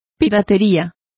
Complete with pronunciation of the translation of piracy.